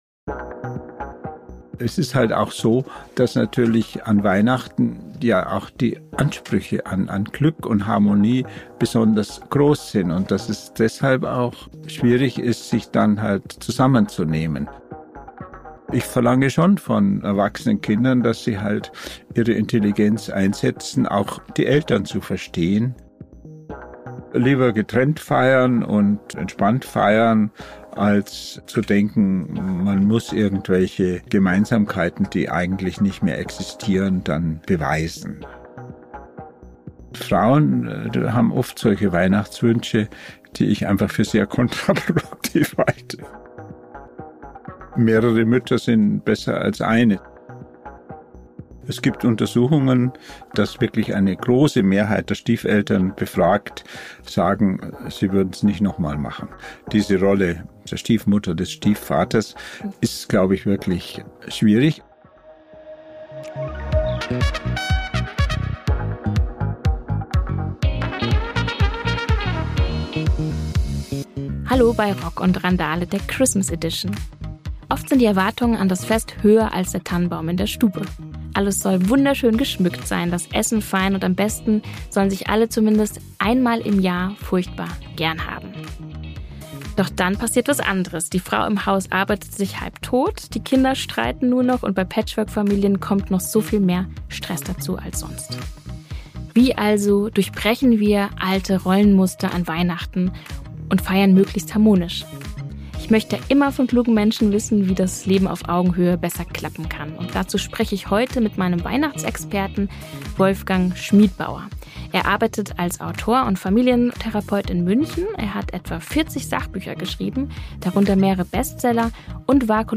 Was können wir also tun, damit an Weihnachten Friede herrscht? Und wie feiert man am besten in Patchwork-Konstellationen? Darüber sprechen wir mit einem der profiliertesten Familientherapeuten und Psychoanalytiker Deutschlands: Wolfgang Schmidbauer, 84.